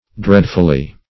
Dreadfully \Dread"ful*ly\, adv.